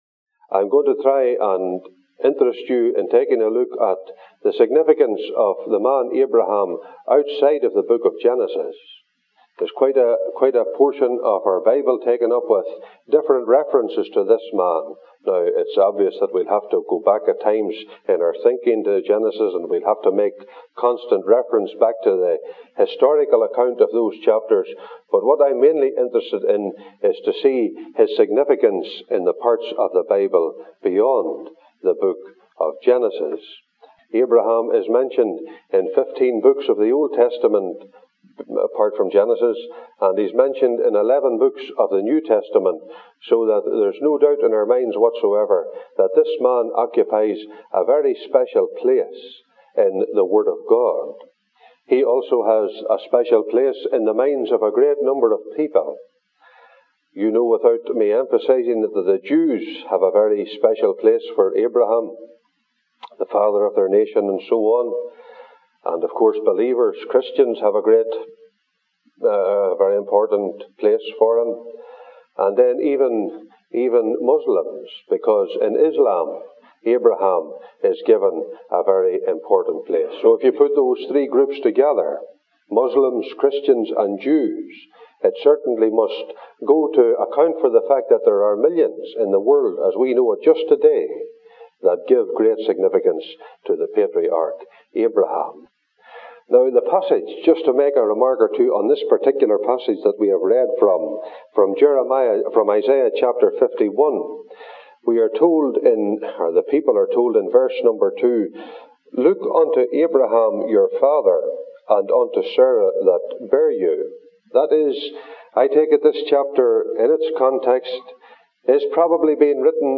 (Recorded in London, Ontario, Canada)